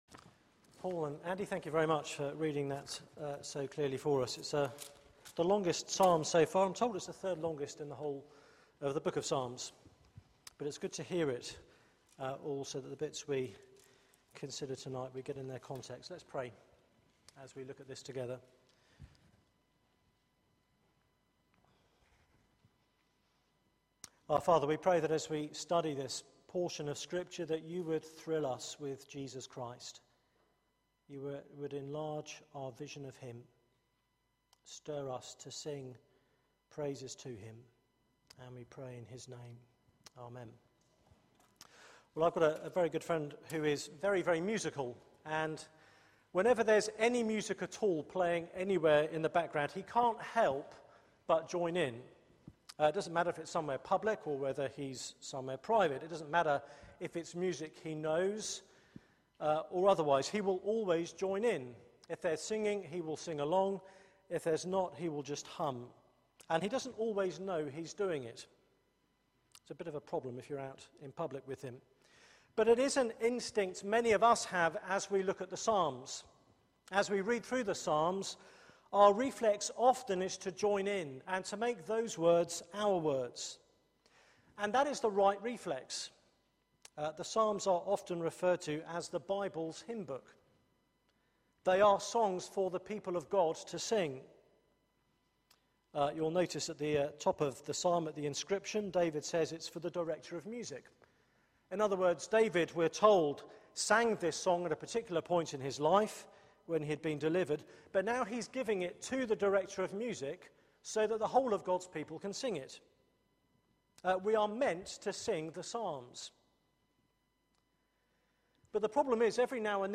Media for 6:30pm Service on Sun 04th Aug 2013 18:30 Speaker
Summer Songs Theme: The Lord is my rock Sermon Search the media library There are recordings here going back several years.